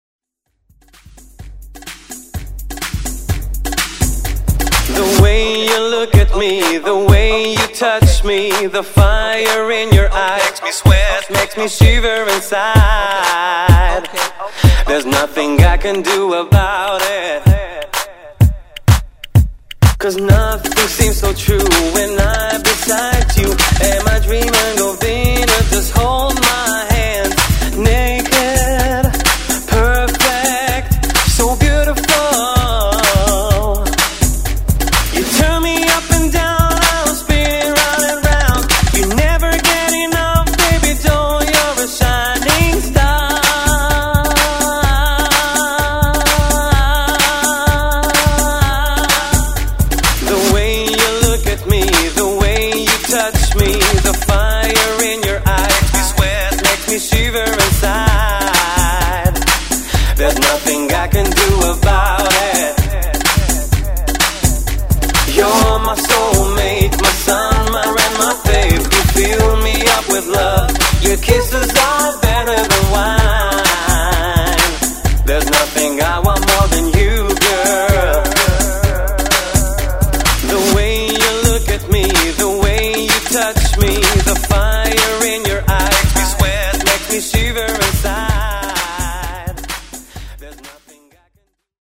Genre: MASHUPS
Clean BPM: 127 Time